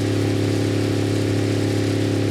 driving.ogg